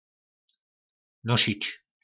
Slovník nářečí Po našimu